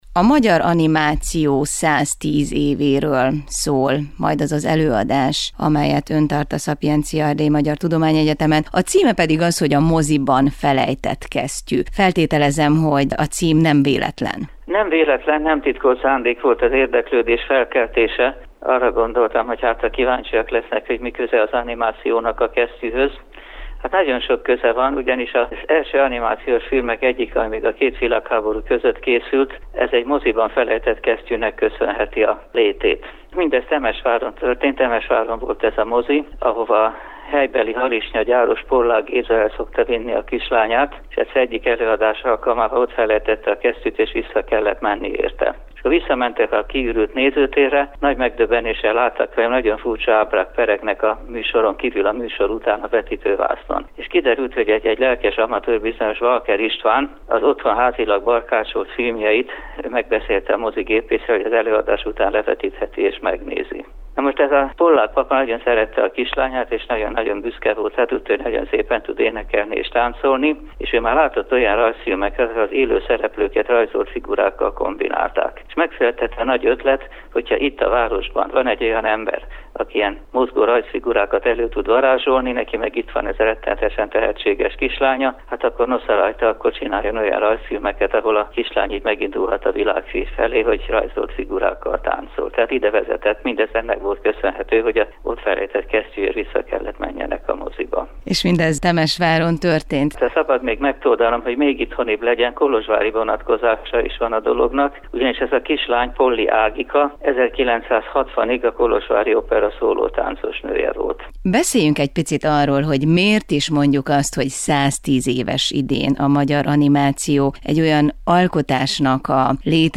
Élő Kolozsvári Rádió